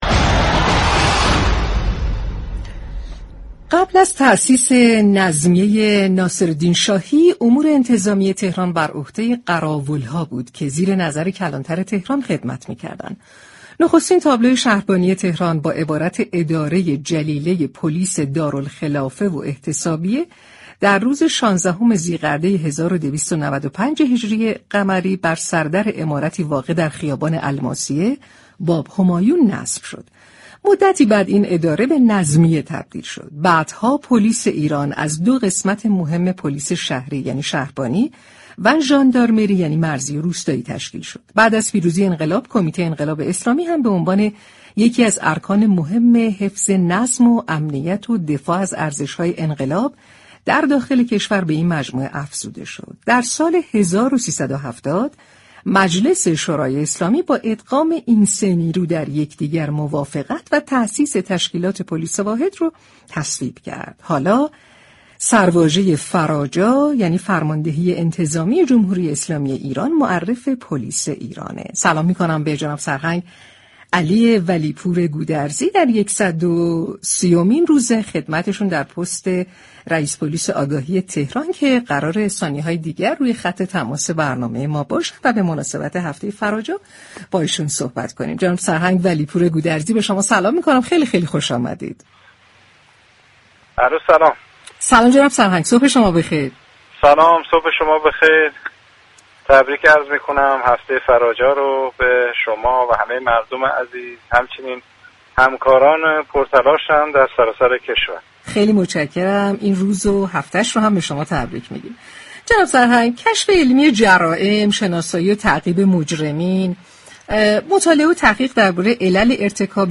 به گزارش پایگاه اطلاع رسانی رادیو تهران، سرهنگ علی ولی پور گودرزی رئیس پلیس آگاهی تهران به مناسبت هفته فراجا در گفت و گو با برنامه شهر آفتاب 16 مهرماه، ضمن تبریك هفته فراجا به تمامی مردم ایران و نیروهای انتظامی به ویژه نیروهای پلیس آگاهی اظهار داشت: پلیس آگاهی یكی از تخصصی‌ترین پلیس‌های فراجا است كه با اشراف اطلاعاتی نسبت به مجرمین و كنترل مجرمین حرفه ای و همچنین پیگیری پرونده جرم‌ها دو هدف را دنبال می‌كند.